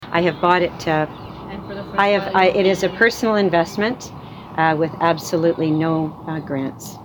Pontiac Warden Jane Toller held a small press conference on Monday morning (April 4) to announce that she has purchased the Cinéma Lyn, located in downtown Fort-Coulonge at 526 rue Baume.